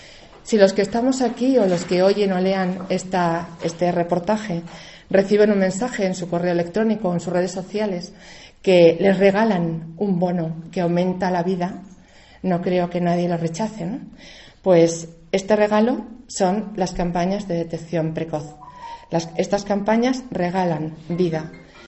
Ha sido durante la presentación de la campaña de detección del cáncer del colón.